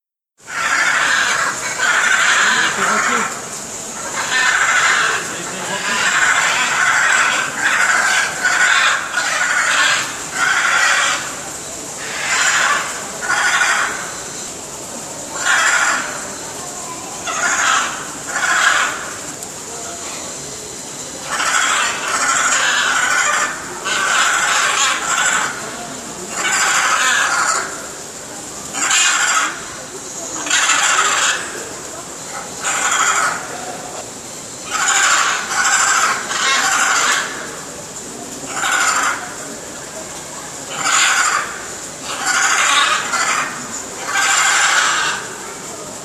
Aras hyacinthe en grande discussion
Le cri du calao La nuit tombe, le calao terrestre arpente son territoire en faisant retentir son cri entêtant (juin 2016)  Son Aras hyacinthe en grande discussion Juillet 2016: le couple d'aras hyancinthe s'approche de la vitre et entame un grand duo de jeux sonores Ecouter
aras-duo.mp3